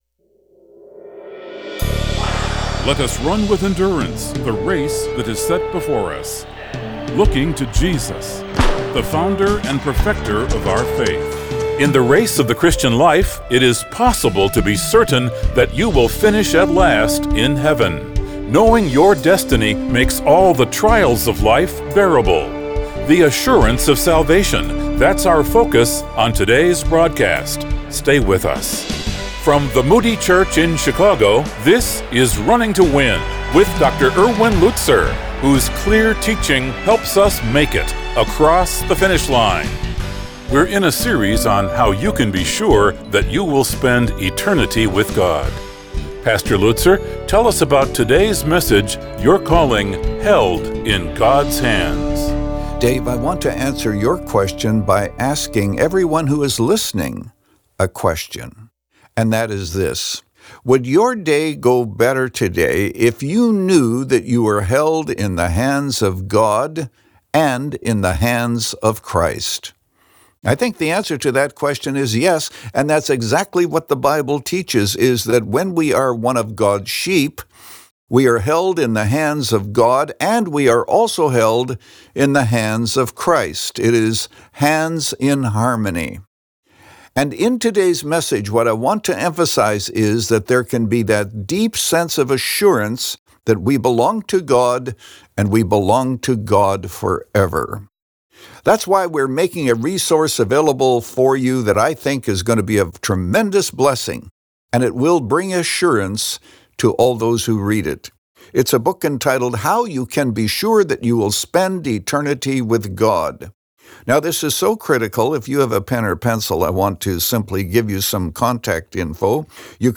Held In God’s Hands – Part 1 of 2 | Radio Programs | Running to Win - 25 Minutes | Moody Church Media